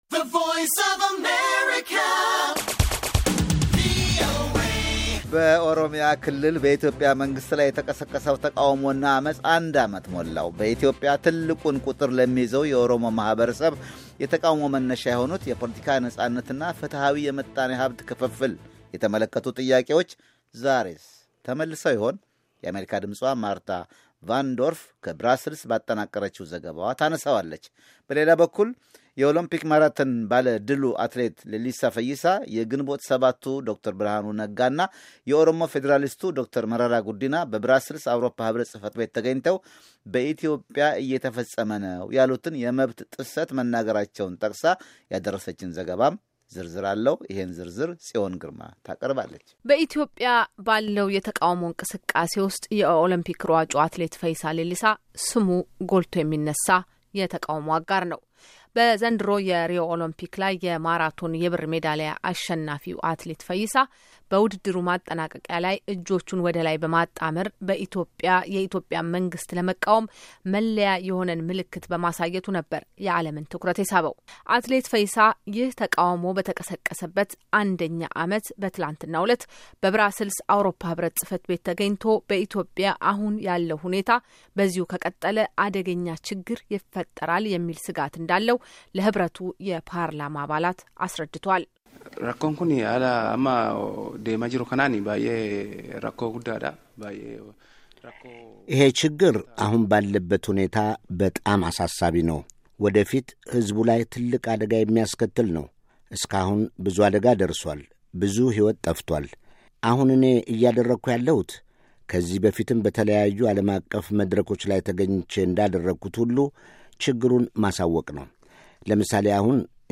በኦሮሚያ ክልል በኢትዮጵያ መንግሥት ላይ የተቀሰቀሰው ተቃውሞና አመጽ አንድ ዓመት ሞላው። በኢትዮጵያ ትልቁን ቁጥር ለሚይዘው የኦሮሞ ማኅበረሰብ የተቃውሞ መነሻ የሆኑት የፖለቲካ ነፃነት እና ፍትሃዊ የምጣኔ ሃብት ክፍፍል ጥያቄዎች ዛሬስ ተመልሰው ይኾን? በዚህ ዘገባ ተካቷል።